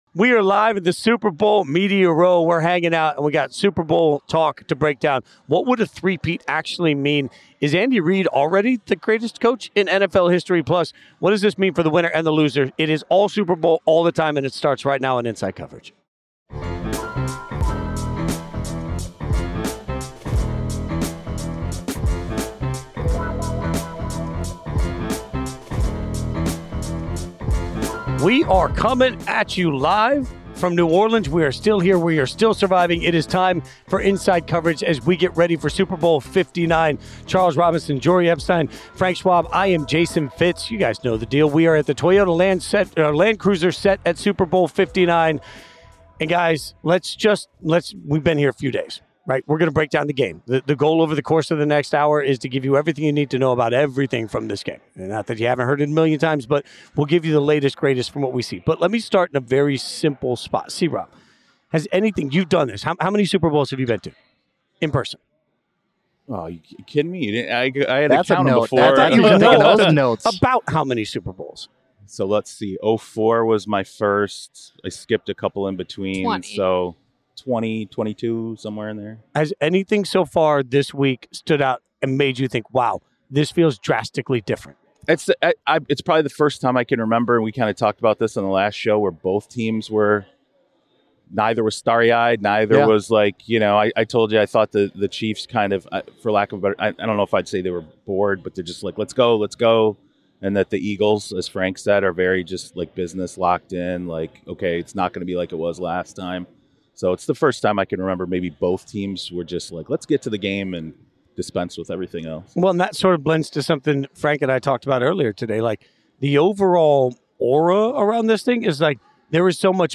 We are live in the Super Bowl media row.